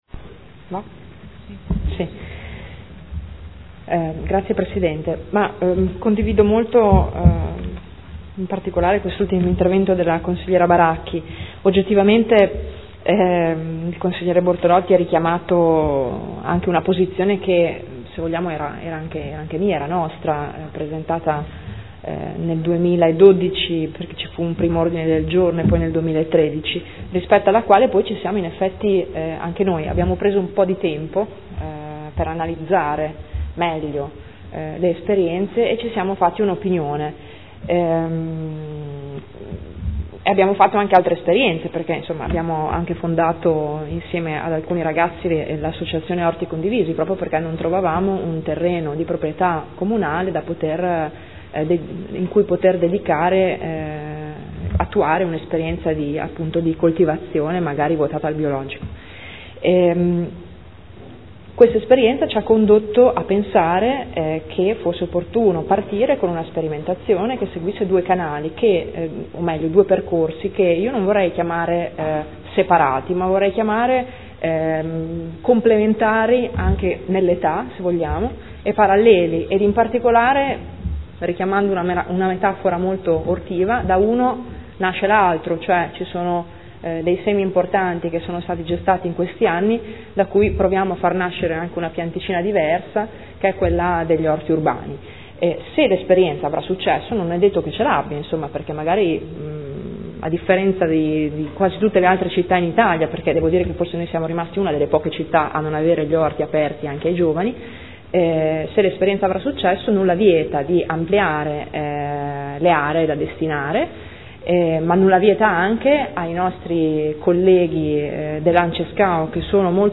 Seduta del 23/07/2015 Replica.